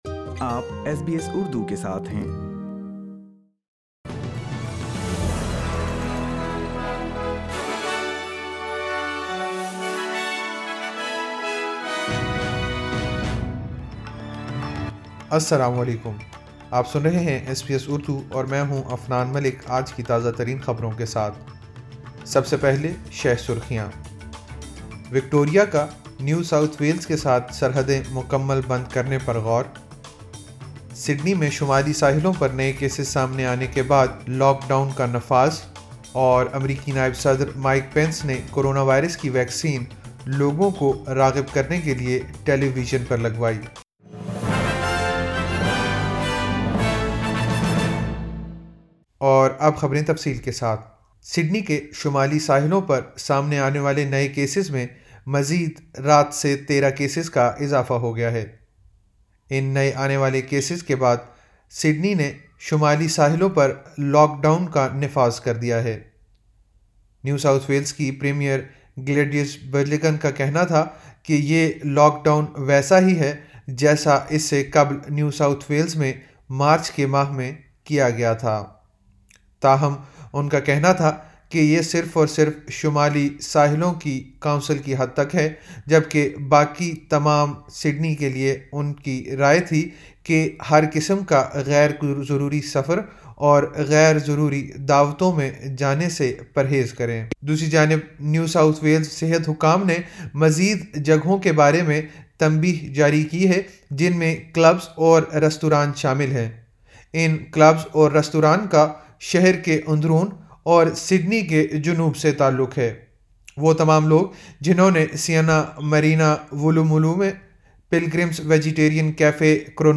ایس بی ایس اردو خبریں 19 دسمبر 2020